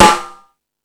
Index of /kb6/E-MU_Pro-Cussion/jazz drums
Jazz Drums(10).wav